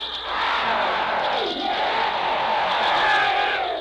Axor-Roar.mp3